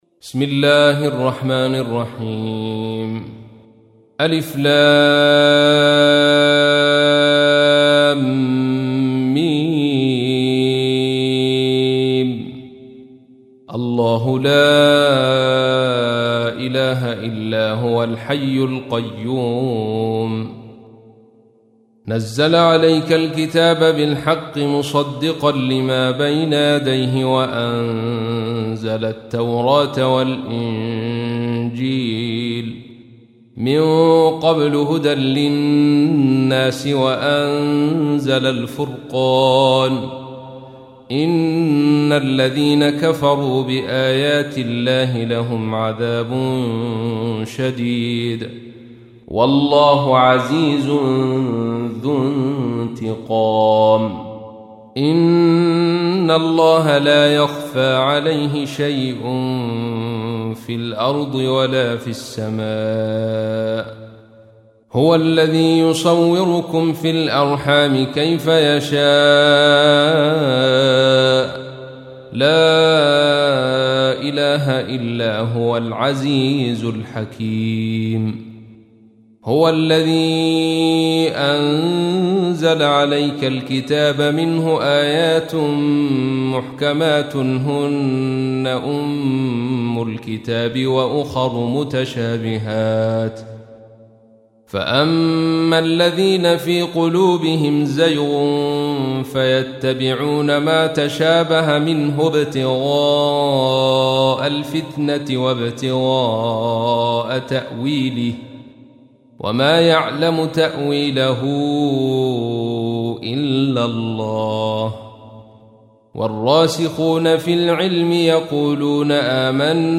تحميل : 3. سورة آل عمران / القارئ عبد الرشيد صوفي / القرآن الكريم / موقع يا حسين